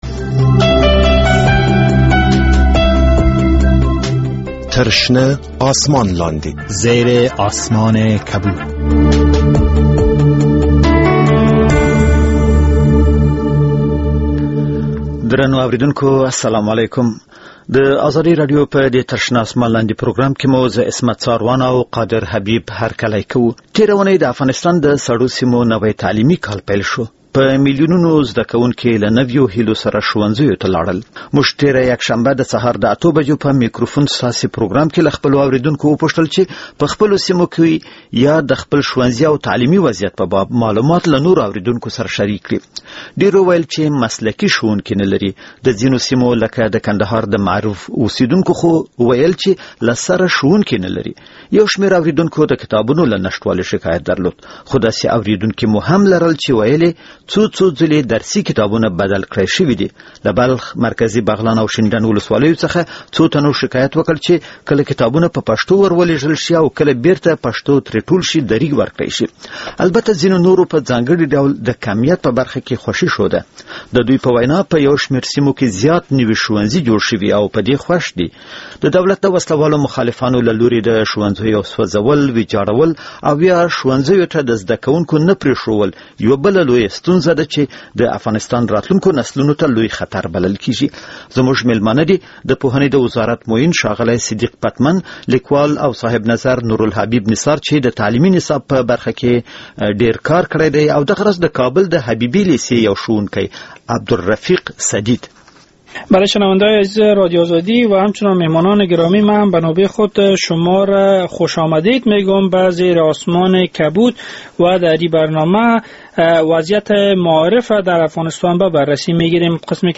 در برنامه این هفتهء در زیر آسمان کبود که به روز شنبه از ساعت سه الی پنج بعد از ظهر به طور زنده از رادیو آزادی پخش شد، وضعیت معارف را در افغانستان به بررسی گرفتیم. ملیون ها طفل به مکتب می روند اما مکتب شان تعمیر ندارد...